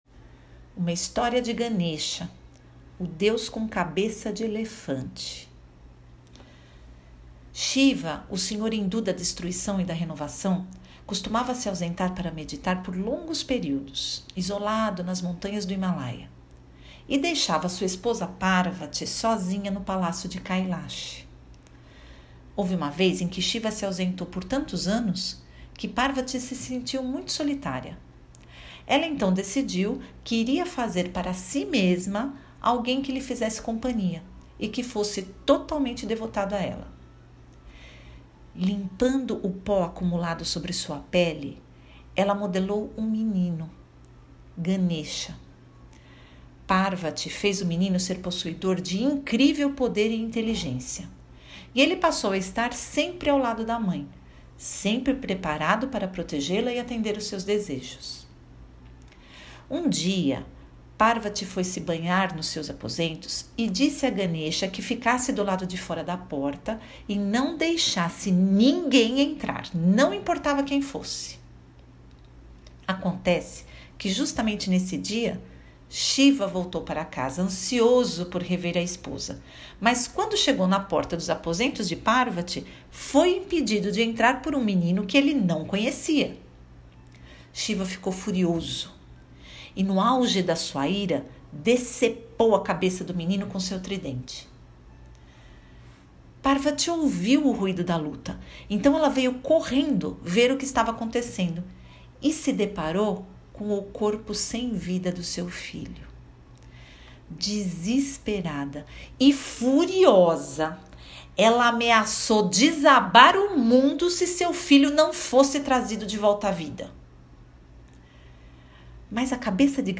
Uma história de Ganesha: o Deus com cabeça de elefante. Pesquisa e Narração